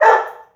koda_bark.wav